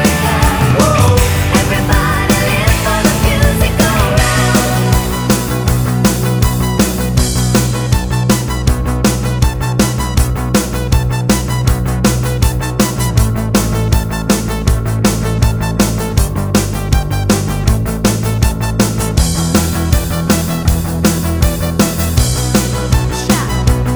No Guitars Pop (1980s) 3:21 Buy £1.50